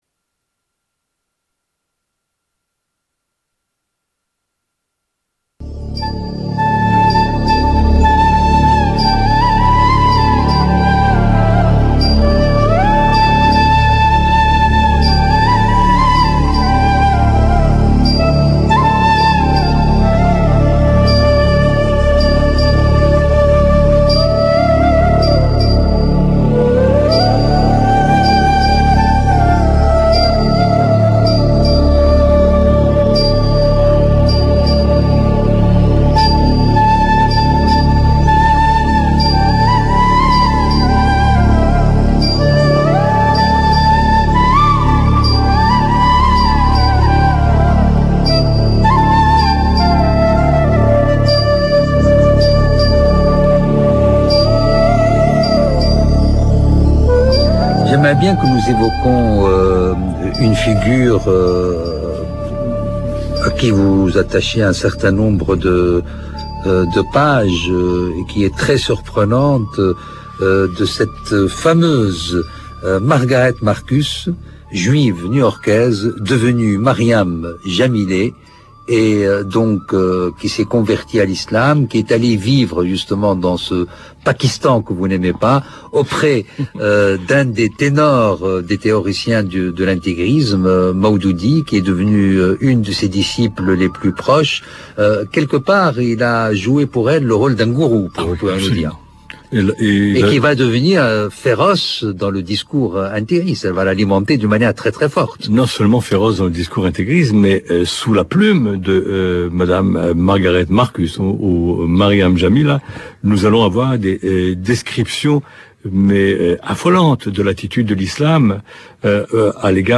Bibliographie : Michel Abitbol, Les Amn�siques, Juifs et Arabes � l�ombre du conflit du Proche-Orient , Ed. Perrin (2005) Invit� Michel Abitbol, historien, de l'universit� h�bra�que de J�rusalem